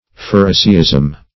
Phariseeism \Phar"i*see*ism\, n.